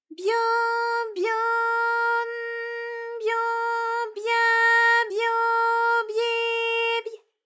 ALYS-DB-001-JPN - First Japanese UTAU vocal library of ALYS.
byo_byo_n_byo_bya_byo_byi_by.wav